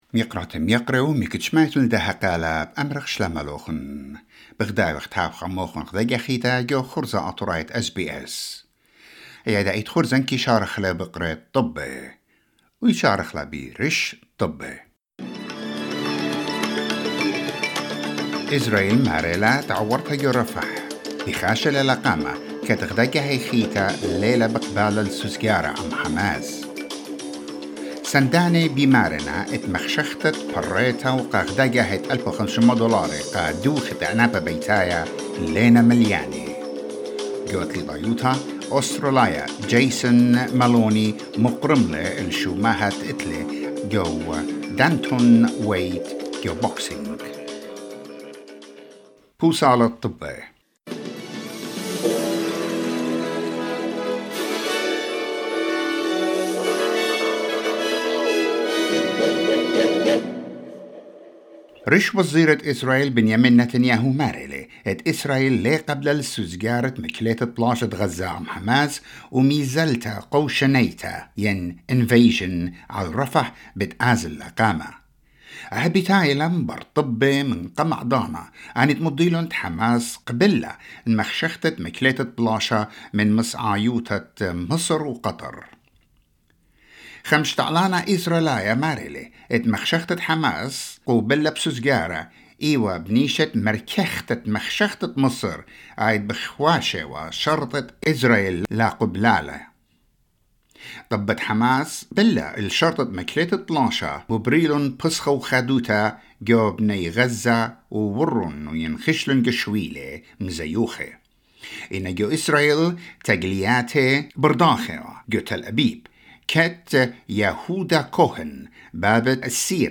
SBS Assyrian news bulletin: 7 May 2024